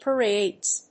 発音記号
• / pɝˈedz(米国英語)
• / pɜ:ˈeɪdz(英国英語)